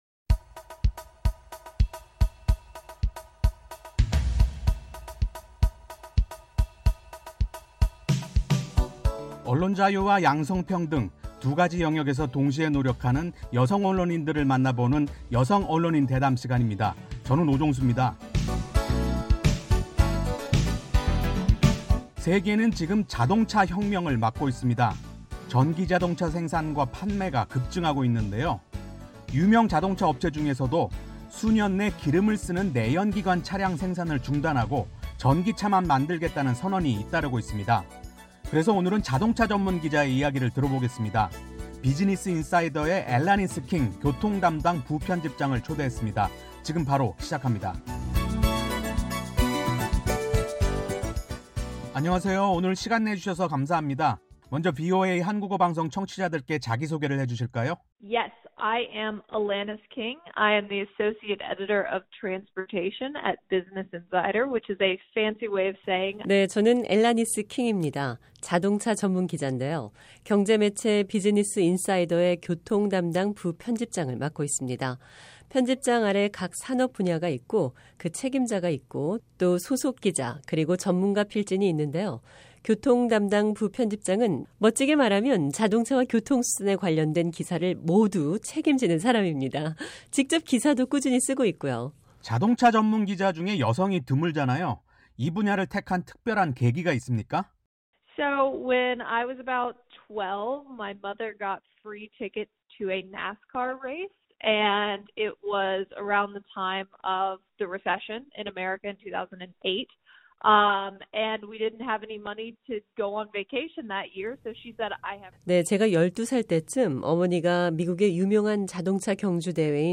[여성 언론인 대담] "전기차 혁명